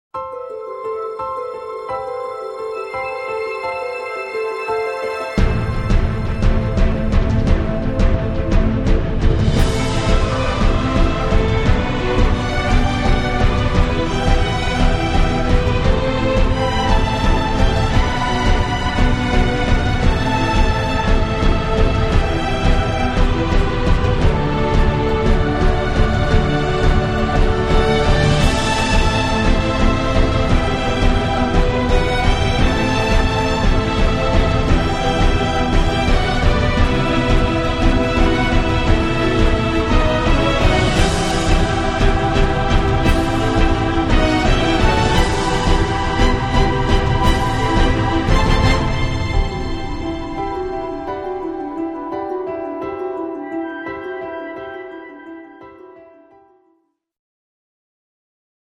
It’s some short mix of 2nd track